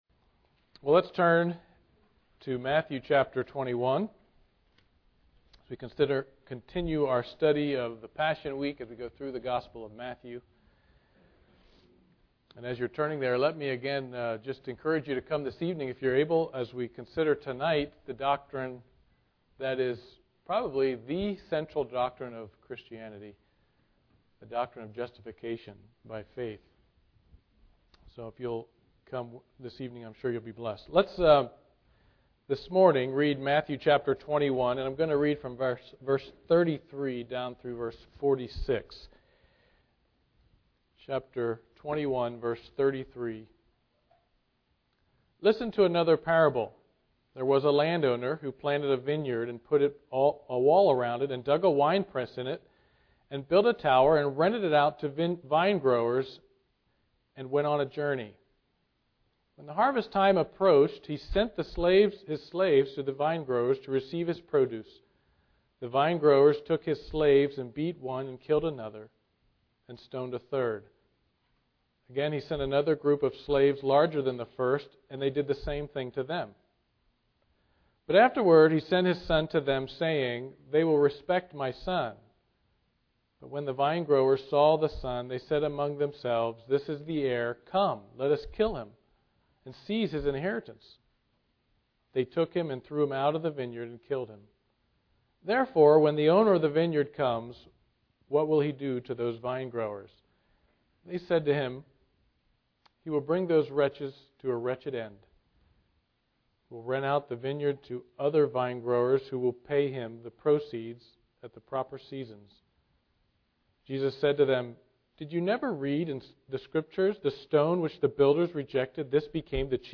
Matthew 21 Service Type: Sunday Morning Worship Topics: Parables of Jesus « Fallen Man